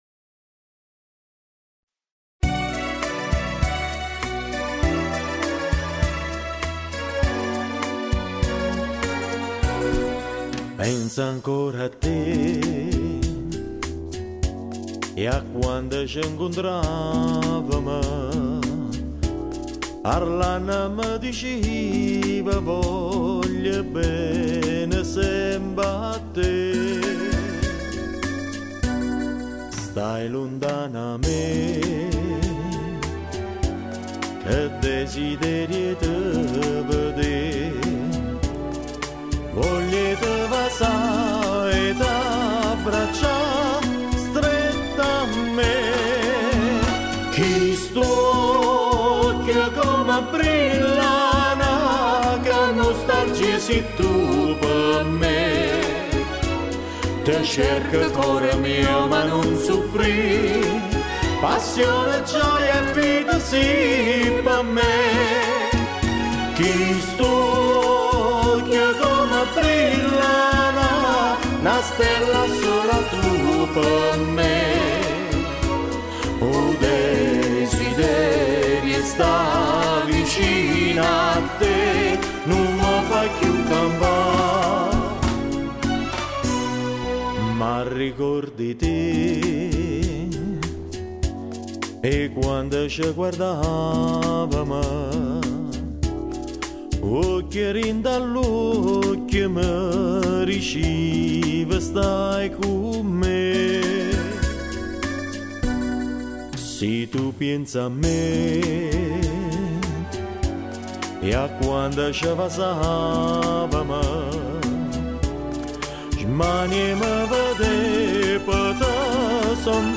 CANZONE NAPOLETANA
stile classico napoletano